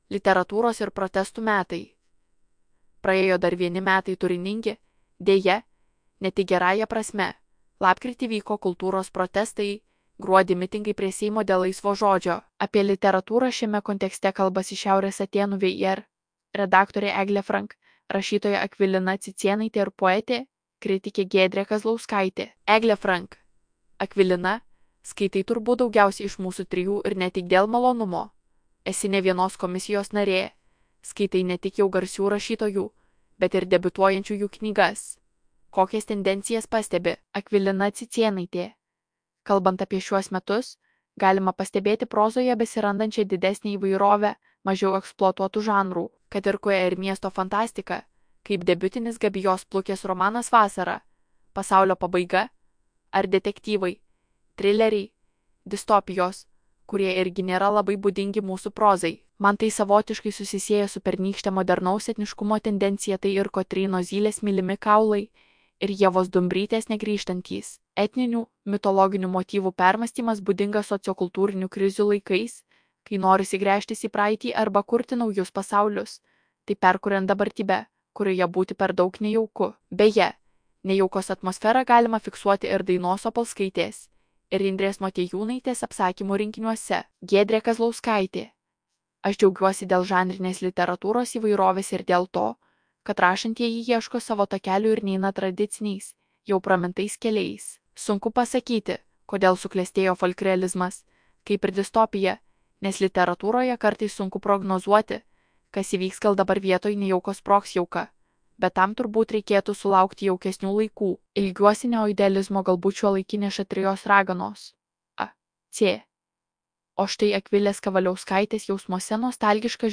tts_protest.mp3